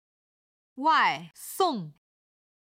これ以前紹介した「 绿豆 」を使った「绿豆汤(lǜ dòu tāng)緑豆スープ」 上海では夏の季節になると登場する一品で、 「スープと」いっても味付けは甘く日本的に言うとゼンザイのような一品で、 スープの中身は「緑豆」と「 ハトムギ 」が入っていています。
今日の振り返り！中国語発声